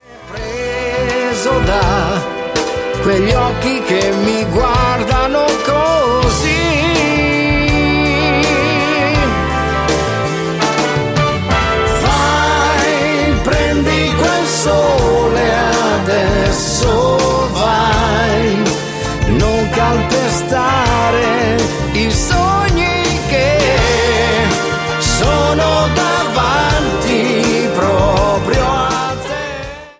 MODERATO POP  (03,53)